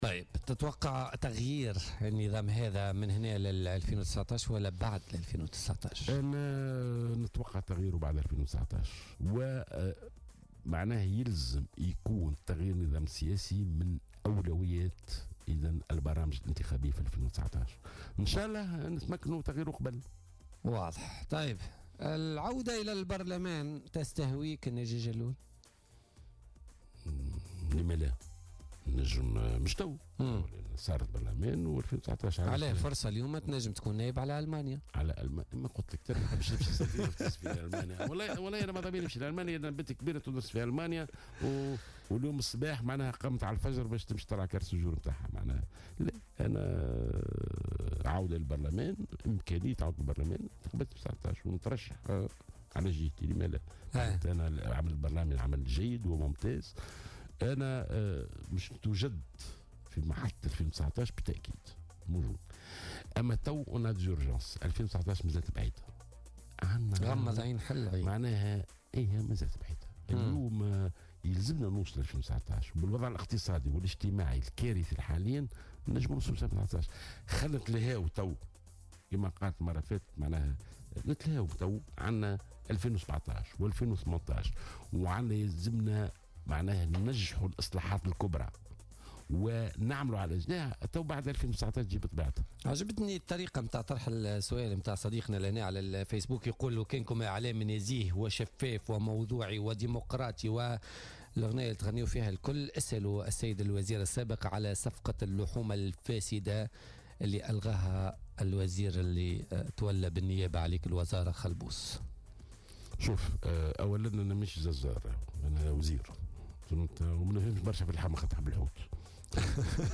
قال وزير التربية السابق ناجي جلول ضيف بولتيكا اليوم الإثنين إنه بالتأكيد سيكون في المحطة الانتخابية سنة 2019 وأنه لا يستبعد فكرة عودته للبرلمان للعمل كنائب مترشح عن جهته معتبرا أن العمل البرلماني جيد وممتاز للغاية .